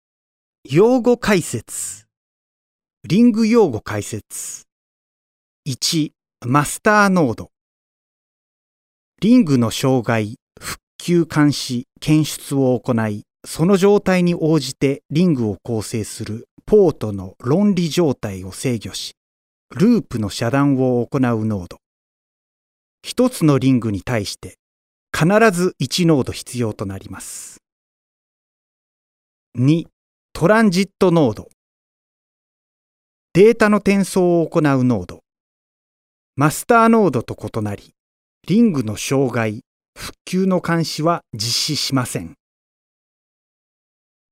Sprecher japanisch. Friendly, Genuine, Happy, Humorous, Natural, Youthful.
Sprechprobe: eLearning (Muttersprache):
Male Japanese Voice Over. Friendly, Genuine, Happy, Humorous, Natural, Youthful.